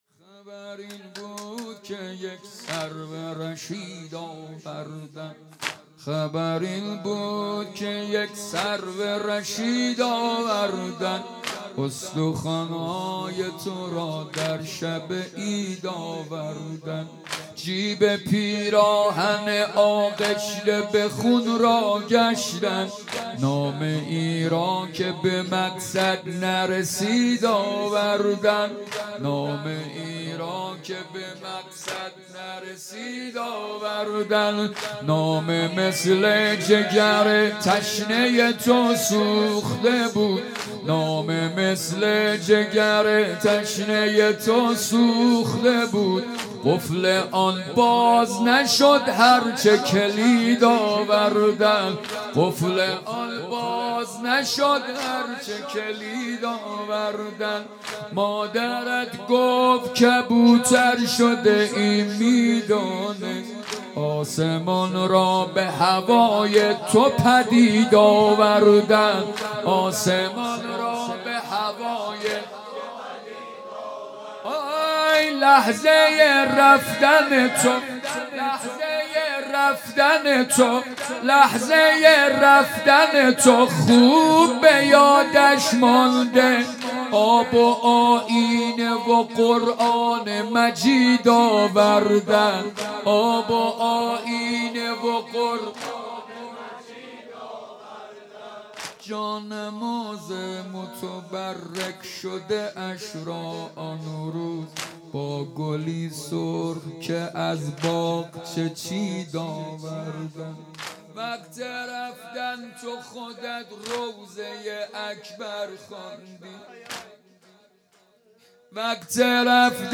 یادواره شهدا | ۱۶ اردیبهشت ۹۶